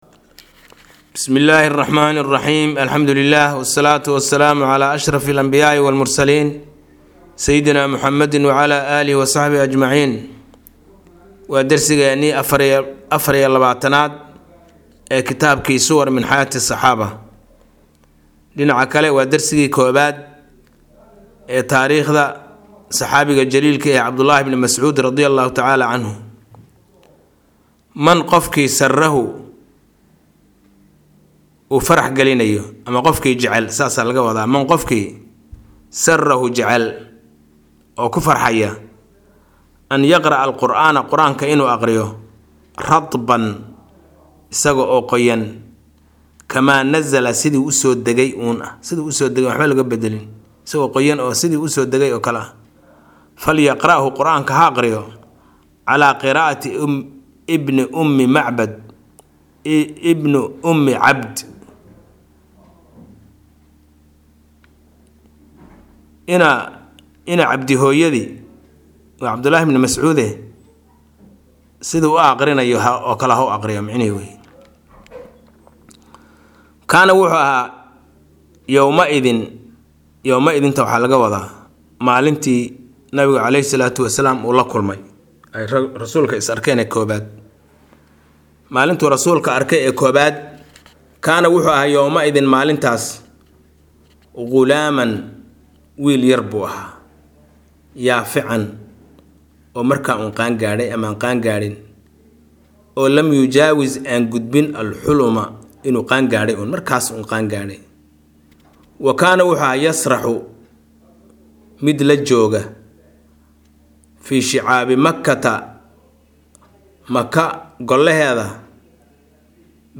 Maqal- Suwar min xayaati saxaabah- Casharka 24aad